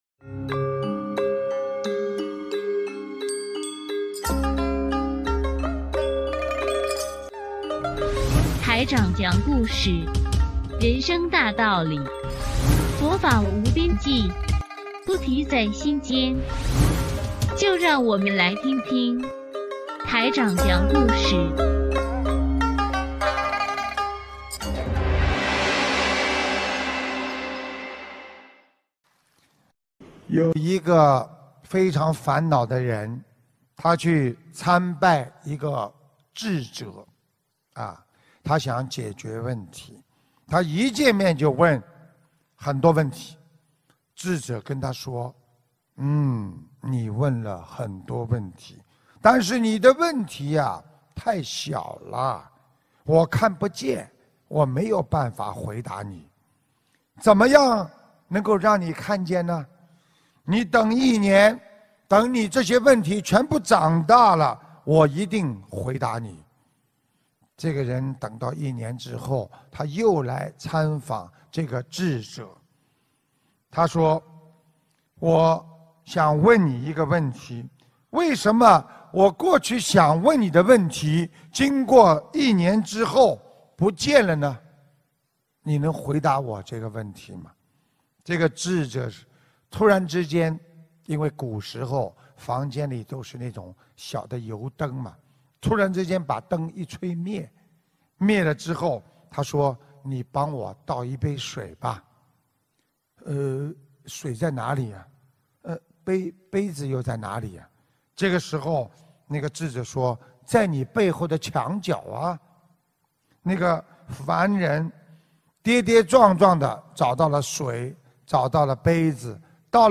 音频：智者与烦人·师父讲小故事大道理